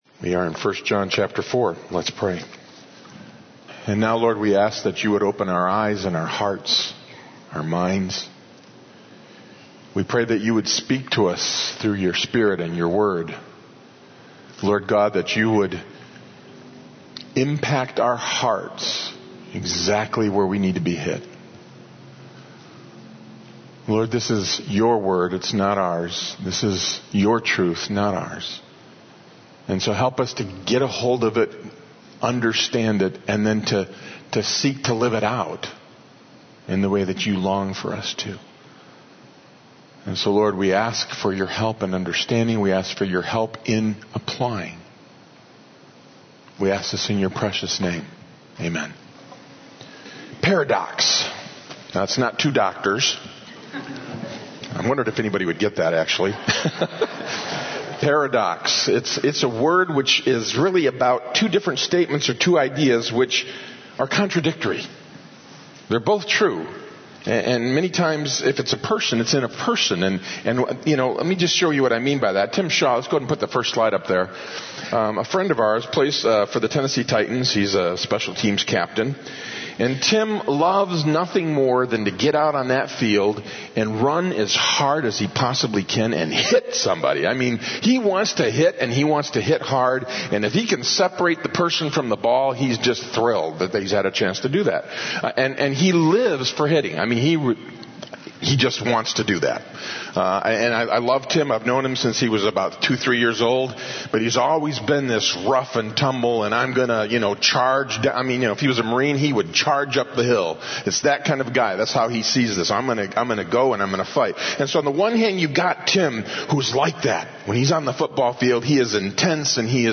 Sermons Archive - Page 47 of 59 - Open Door Bible Church